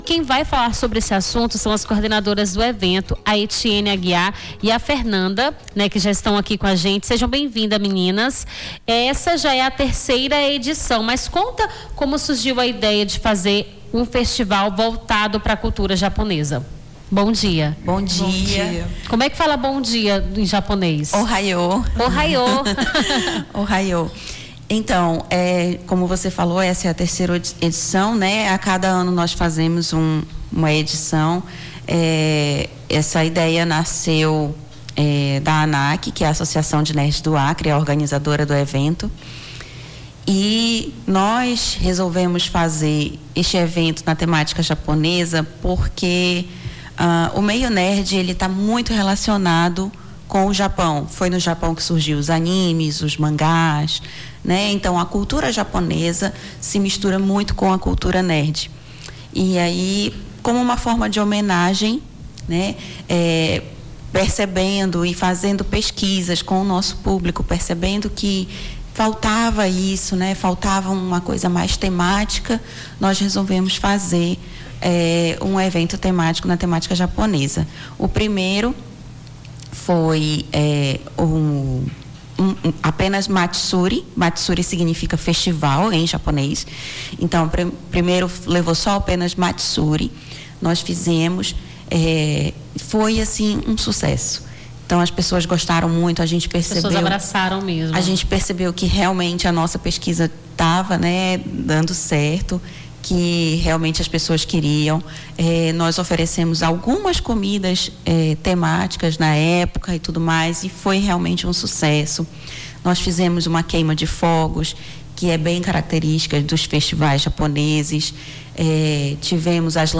Nome do Artista - CENSURA - ENTREVISTA (3 EDIÇÃO DO FESTIVAL JAPONÊS DO ACRE) 26-06-25.mp3